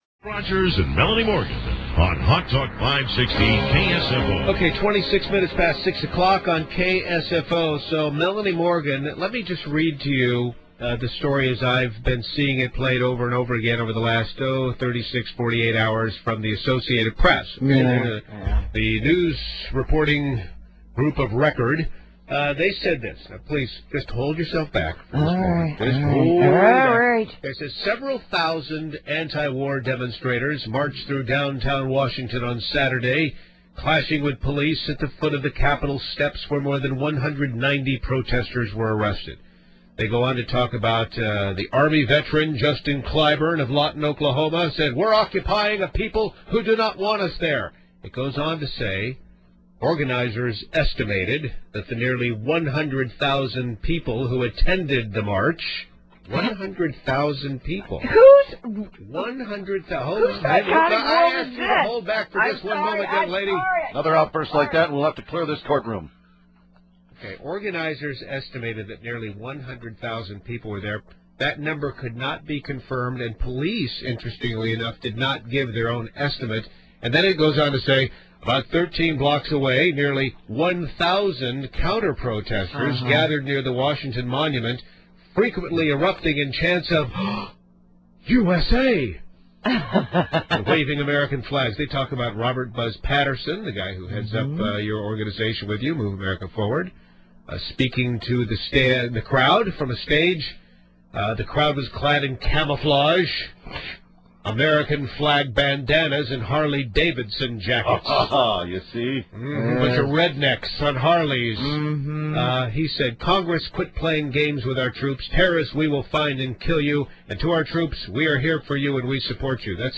You can hear how happy she was that an anti-war protester got punched.
(She sounds like she envisions some kind of Jets/Sharks rumble.)